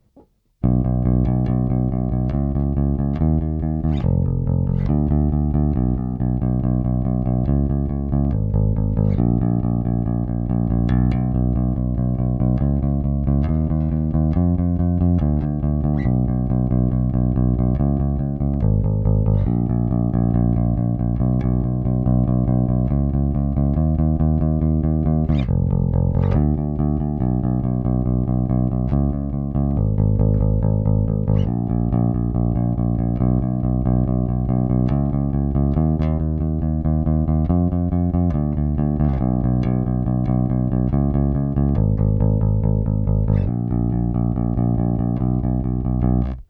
Bassriff-Quiz
Anhang anzeigen 687565 Zum Vergrößern anklicken.... klingt als würds mir gefallen denke mal nicht SO neu, leicht indie, aber mit etwas Gitarre, guter Drive